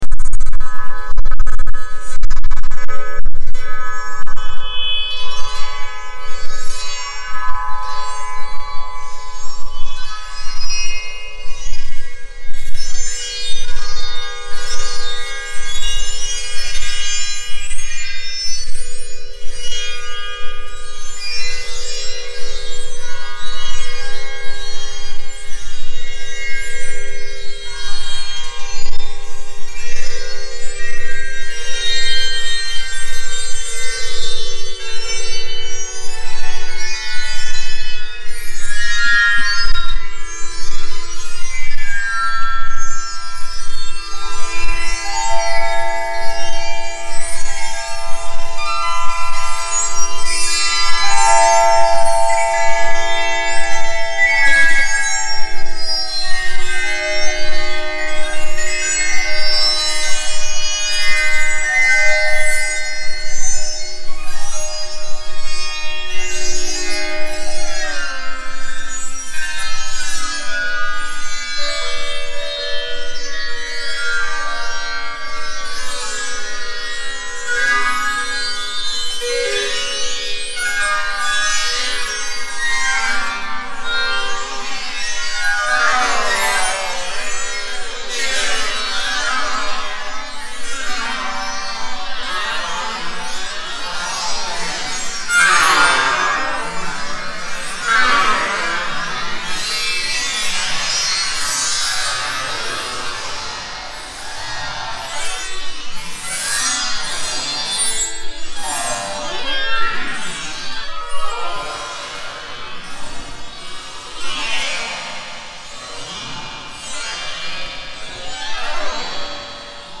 The choice of the sitar is incidental.
MoaningSitar02.mp3